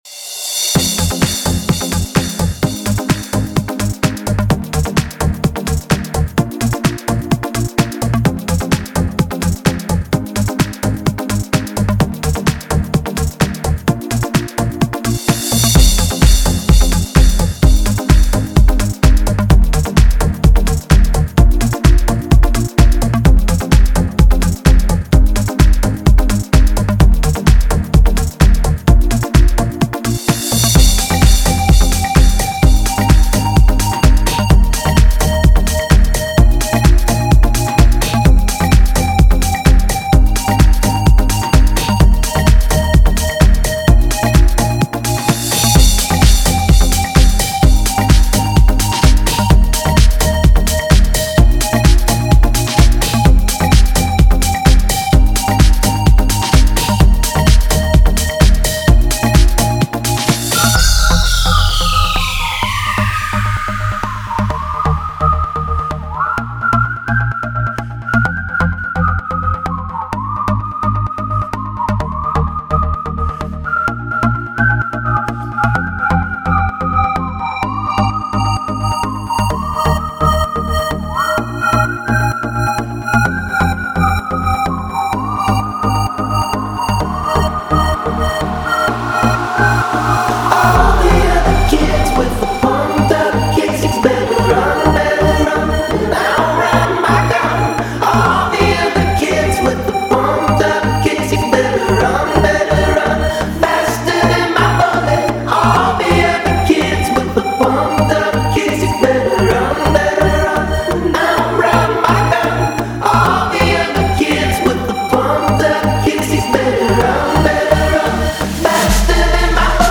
Drum and Bass